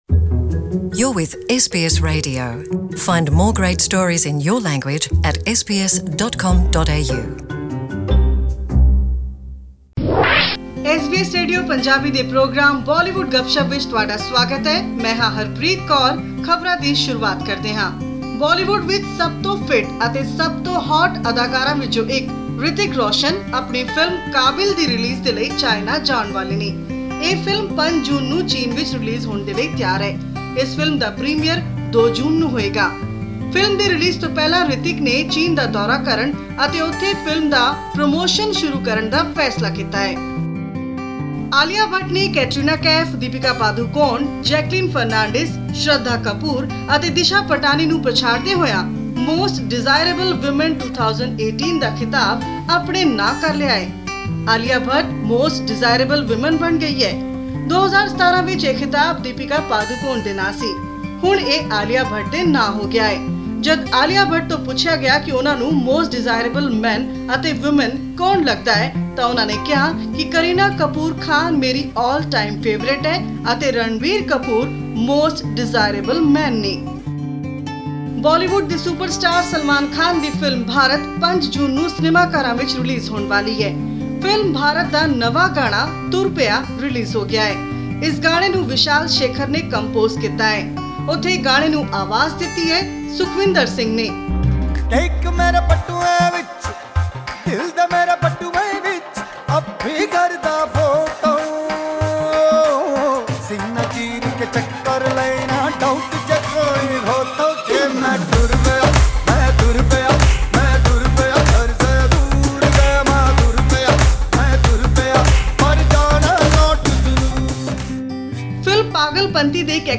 Listen to Bollywood Gupshup and enjoy some music clips of upcoming Bollywood films.